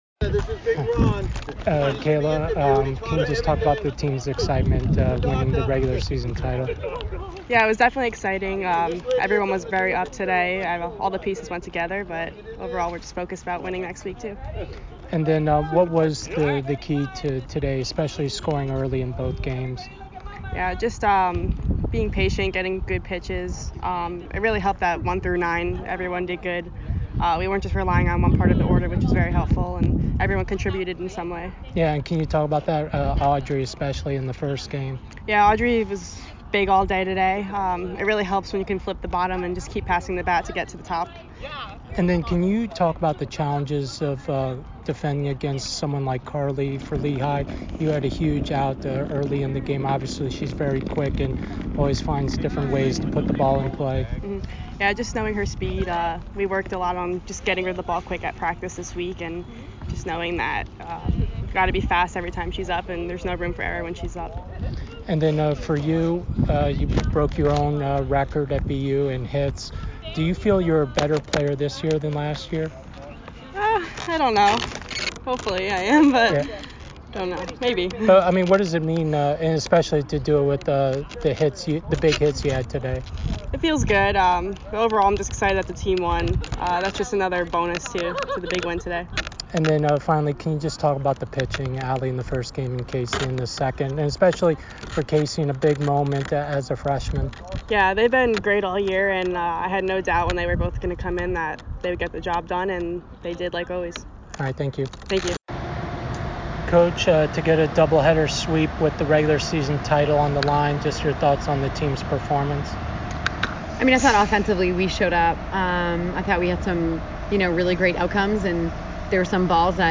Lehigh DH Postgame Interview